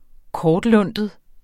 Udtale [ -ˌlɔnˀdəð ]